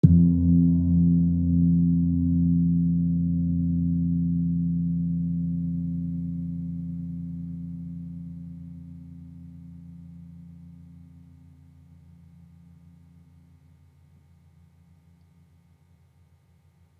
Gamelan Sound Bank
Gong-F1-f.wav